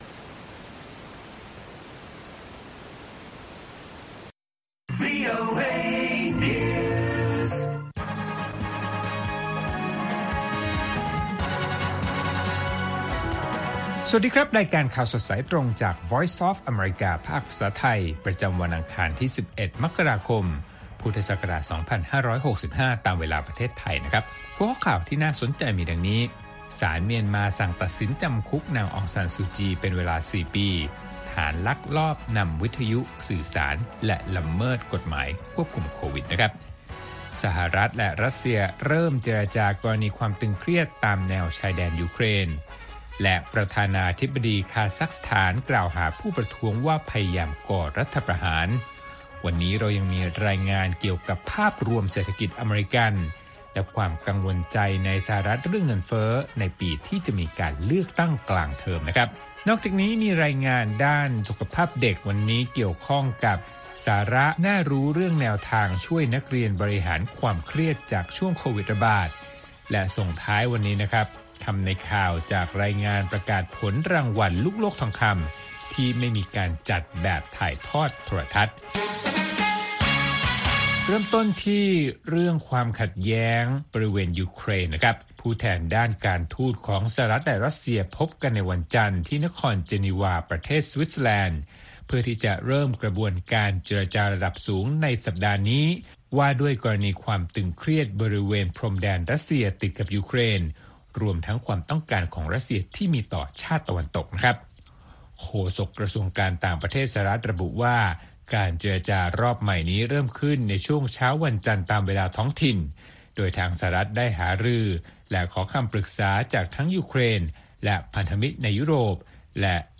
ข่าวสดสายตรงจากวีโอเอ ภาคภาษาไทย ประจำวันอังคารที่ 11 มกราคม 2565 ตามเวลาประเทศไทย